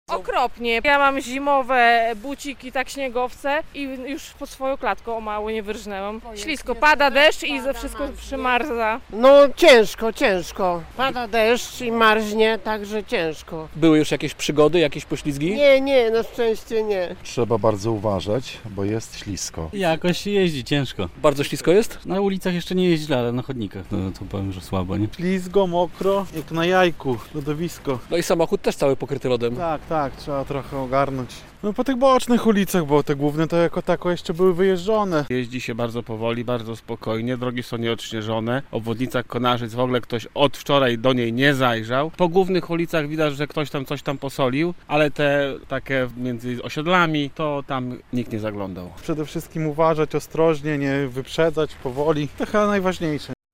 Trudne warunki pogodowe w Łomży - relacja